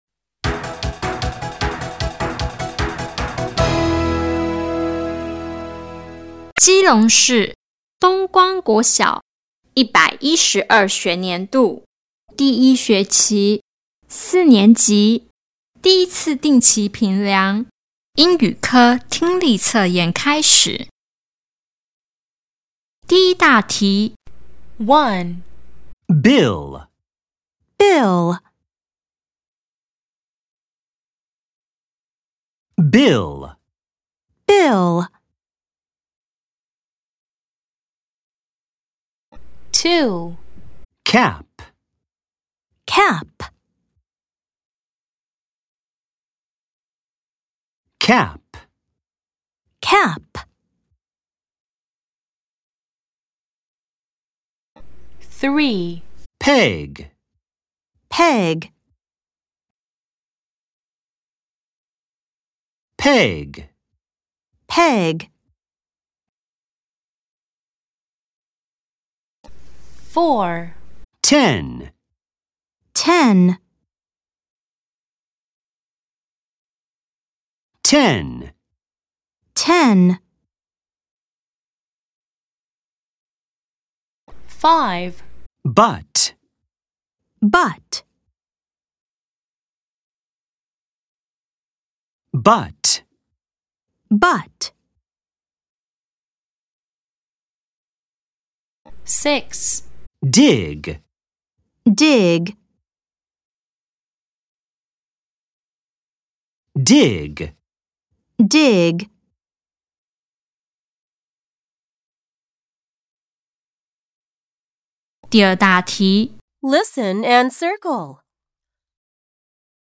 第1次定期評量_英語聽力測驗.mp3